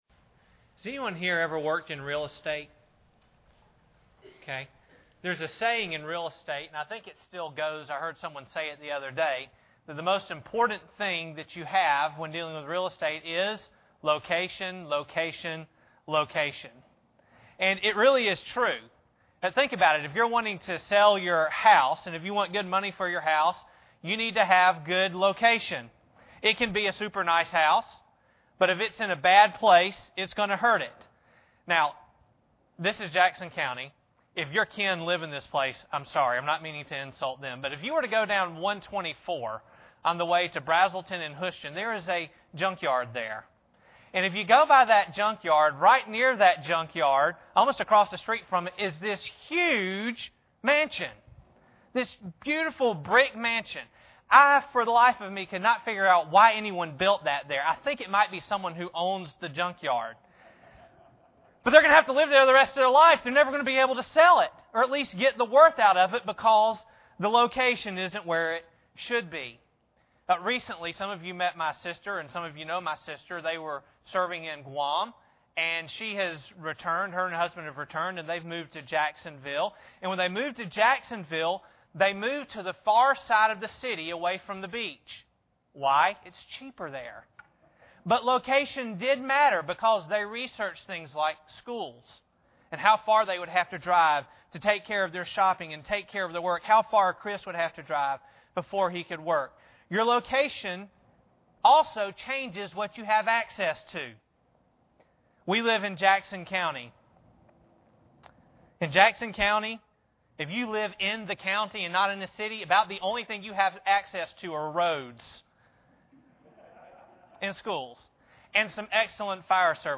Ephesians 1:3 Service Type: Sunday Morning Bible Text